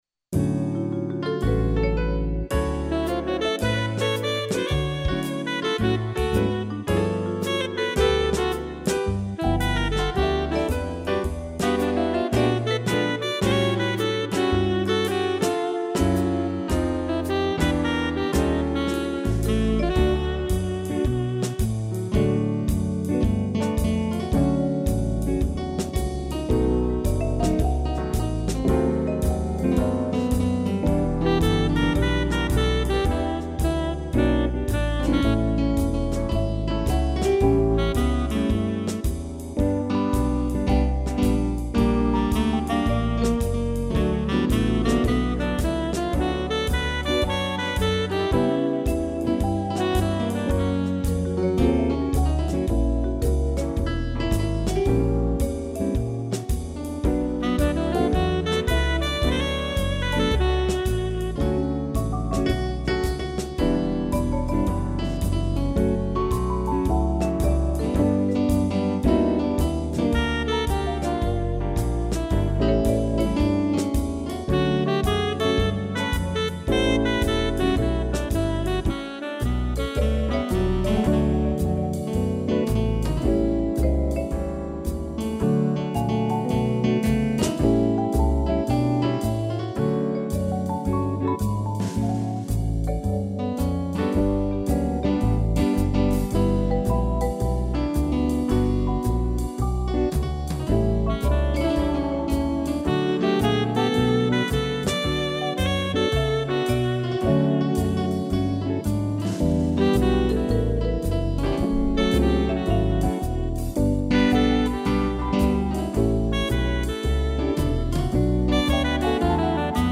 piano e trombone